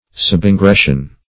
Subingression \Sub`in*gres"sion\